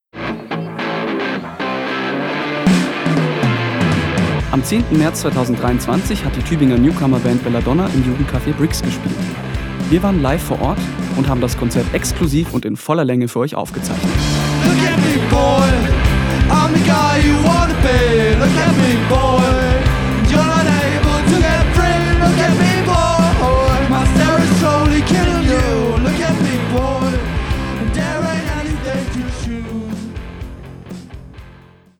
Die Bude war voll, die Stimmung am Überkochen.